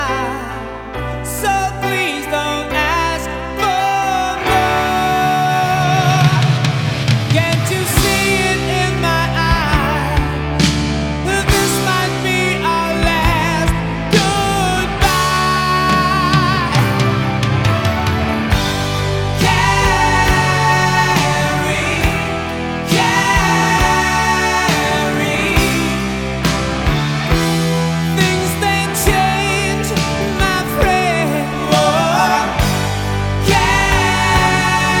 Жанр: Пост-хардкор / Хард-рок / Рок